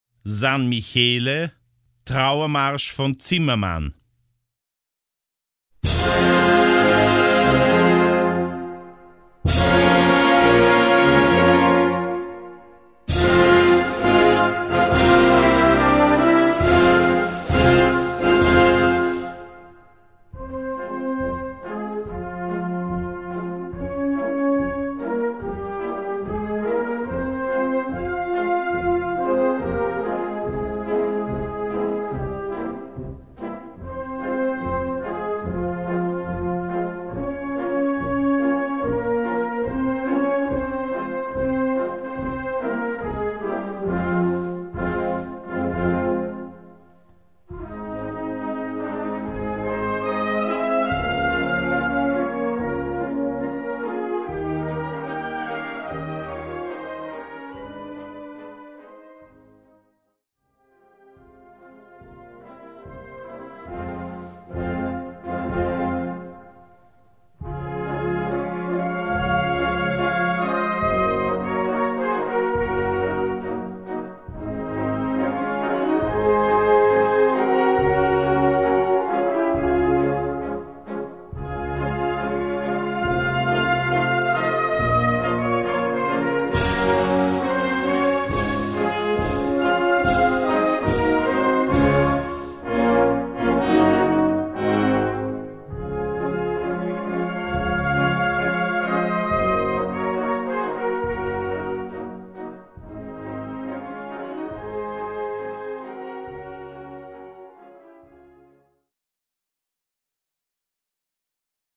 Gattung: Trauermarsch
3:29 Minuten Besetzung: Blasorchester Zu hören auf